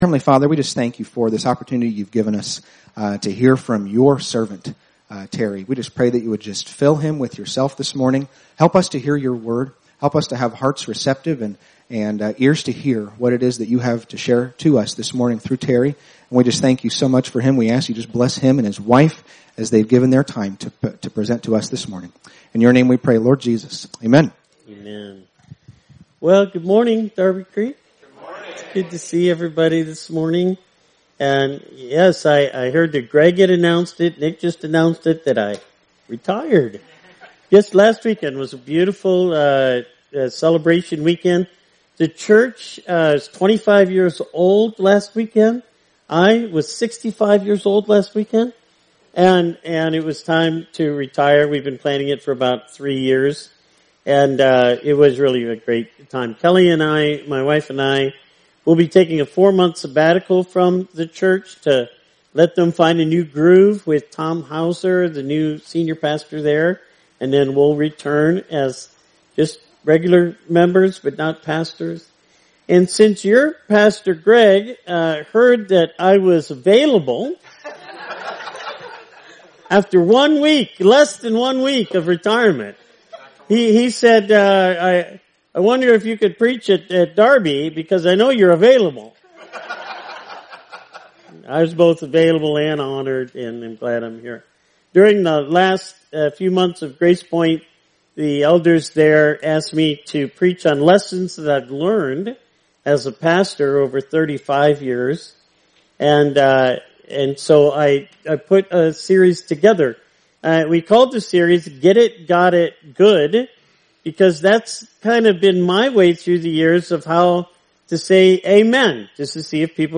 From Series: "Stand Alone Sermons"
Sermons that are not part of a series